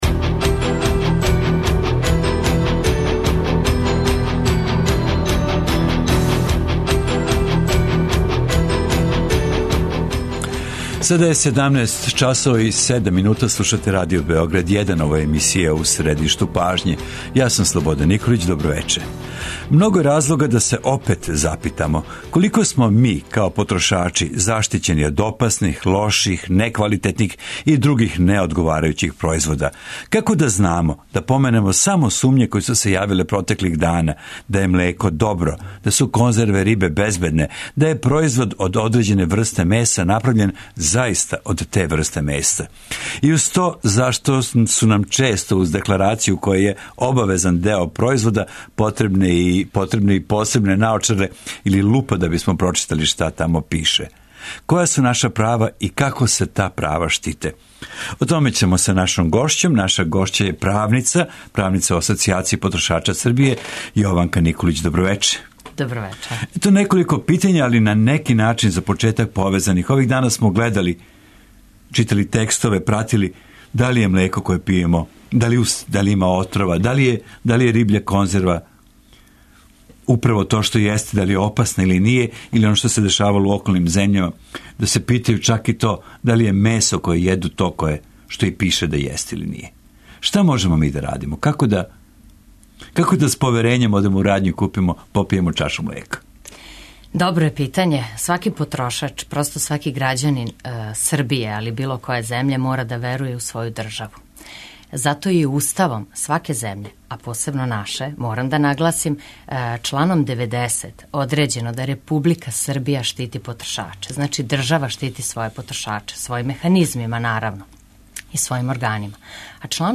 У овој емисији такође је важно и мишљење наших слушалаца.